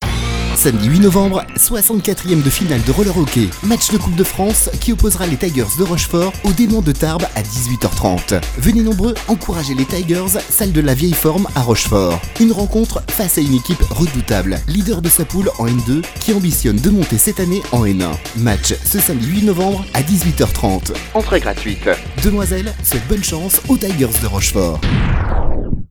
L’annonce du match de coupe de France de ce weekend face aux Desmans de Tarbes faite par notre radio partenaire Demoiselle FM.